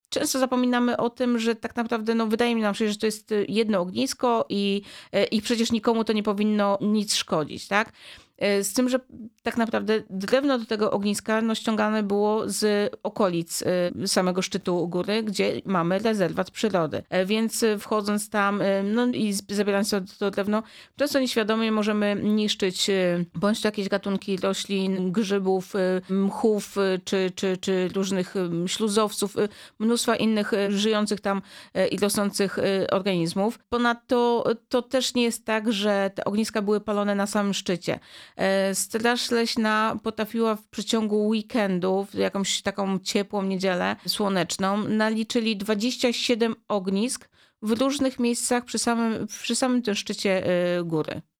Zbliżający się Sylwester, rosnąca popularność górskich wędrówek, potrzeba ochrony przyrody oraz 5. edycja akcji „Choinka dla Życia” – to główne tematy rozmowy w studiu Radia Rodzina.